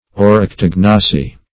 Search Result for " oryctognosy" : The Collaborative International Dictionary of English v.0.48: Oryctognosy \Or`yc*tog"no*sy\, n. [Gr.
oryctognosy.mp3